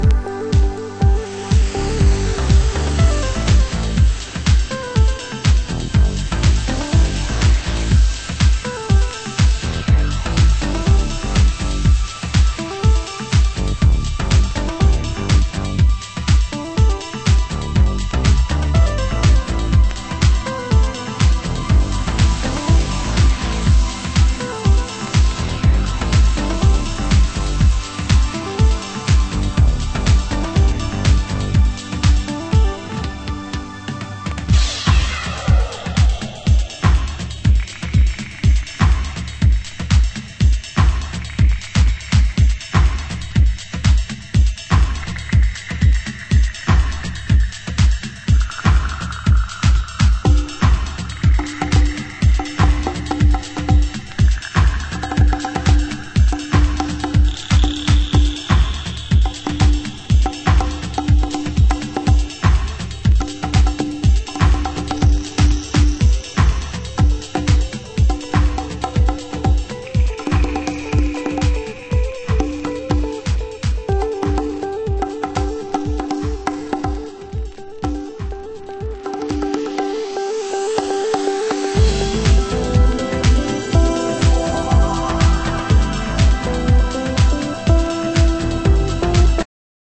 盤質：チリパチノイズ有/A面に線の傷に寄るクリックノイズ有